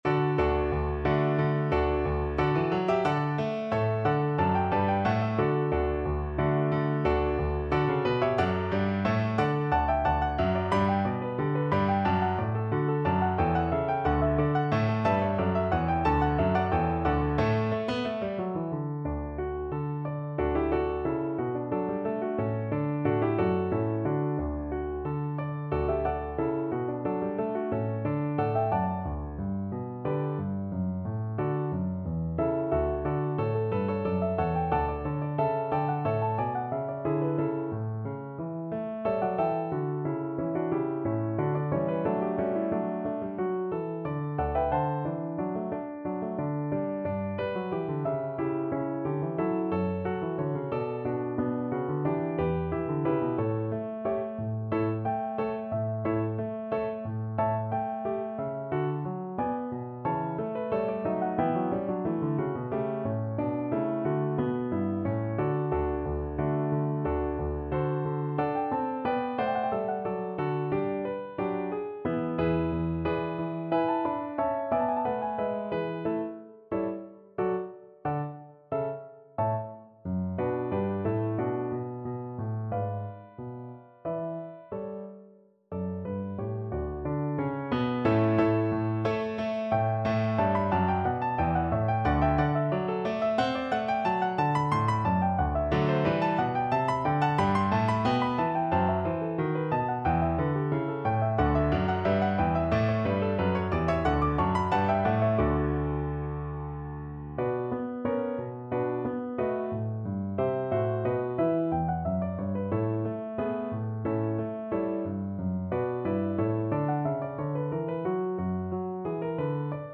Play (or use space bar on your keyboard) Pause Music Playalong - Piano Accompaniment Playalong Band Accompaniment not yet available reset tempo print settings full screen
2/4 (View more 2/4 Music)
D major (Sounding Pitch) (View more D major Music for Bass Voice )
Classical (View more Classical Bass Voice Music)